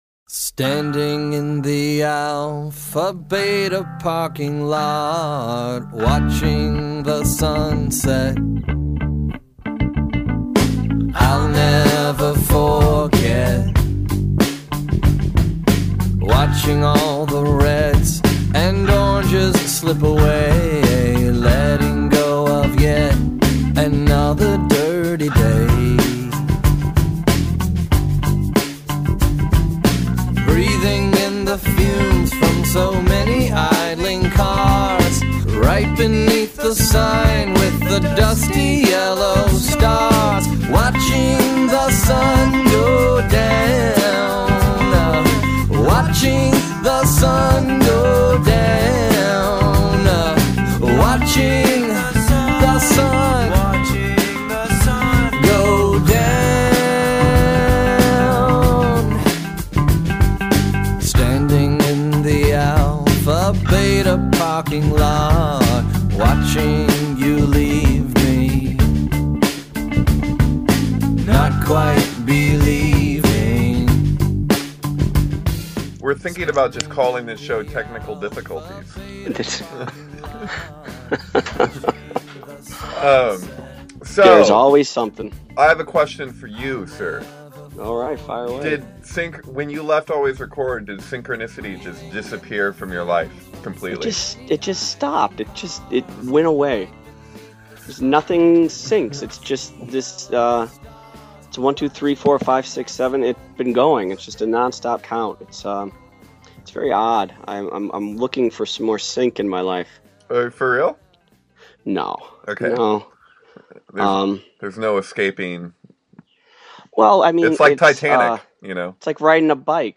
A free-form open roundtable discussion.